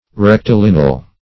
Rectilineal \Rec`ti*lin"e*al\ (-l?n"?*al), Rectilinear